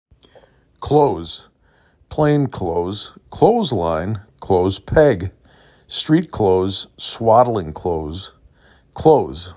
7 Letters, 1 Syllable
k l O D z
k l O z